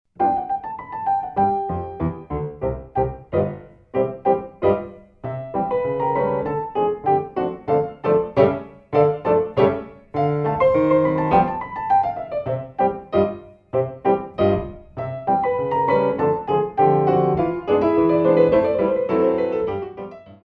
Exercice De Relevés Bonus